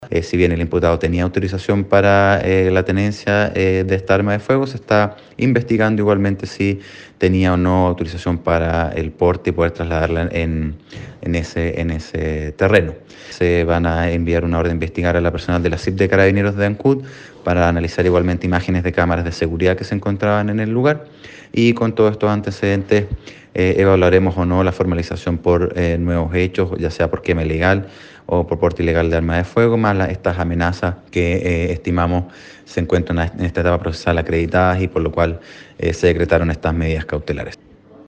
Precisó el persecutor que en este momento se encuentran investigando si contaba o no con la autorización para el porte del arma de fuego, entre otras consideraciones respecto de este caso.